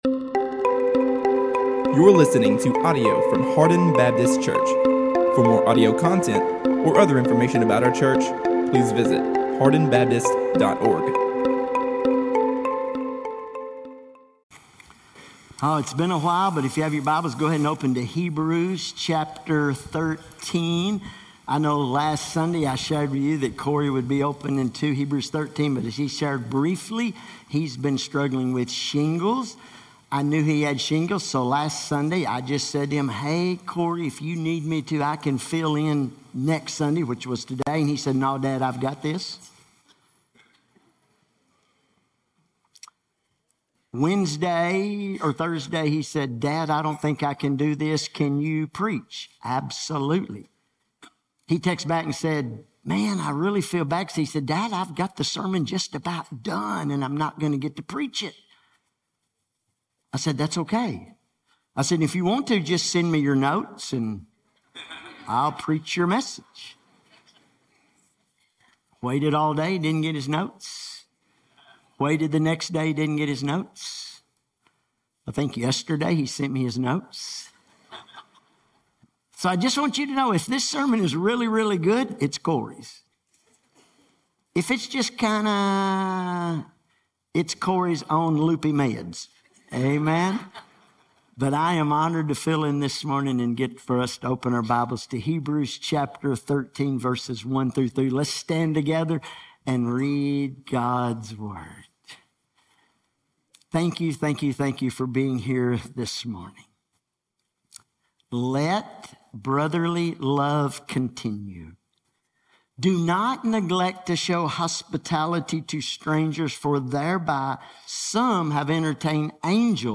A message from the series "Hebrews 2025."